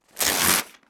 Arrancar una hoja de papel de un block